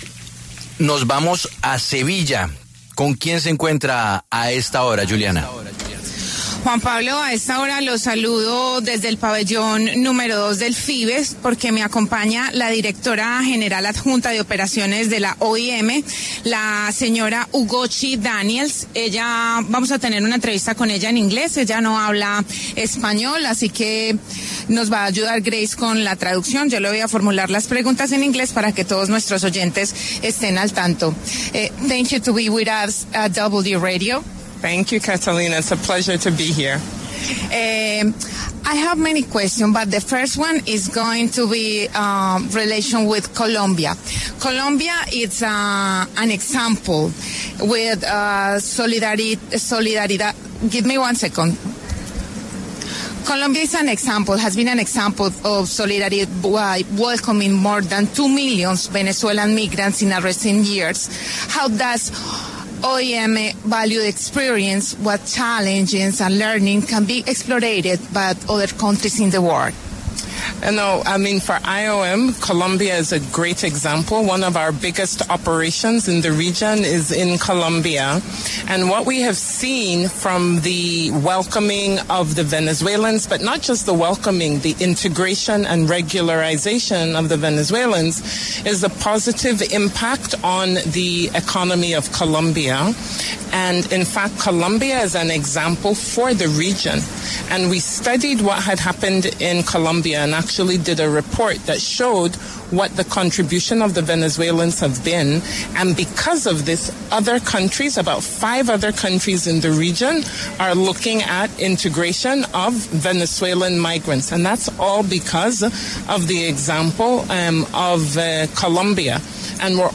Desde la Conferencia de la ONU en Sevilla, España, La W conversó con Ugochi Daniels, directora general adjunta de Operaciones de la OIM.